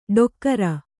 ♪ ḍokkara